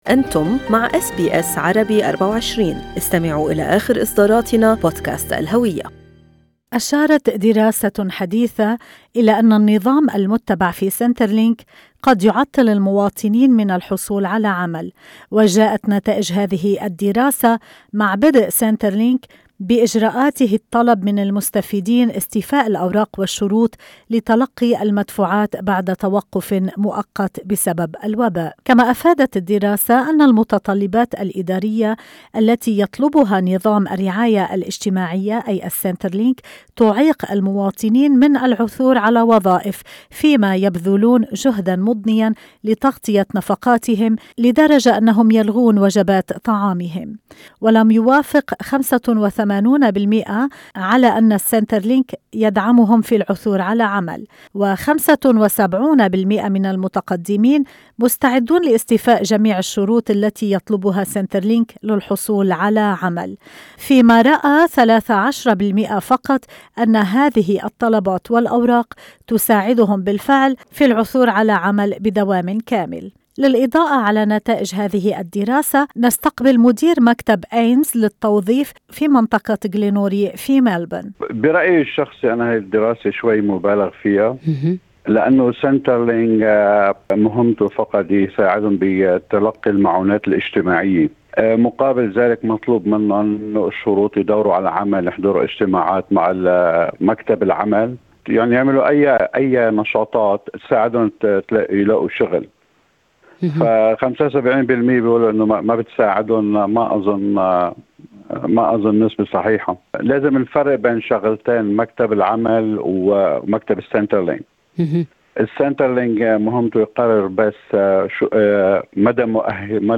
وفي لقاء له مع أس بي أس عربي24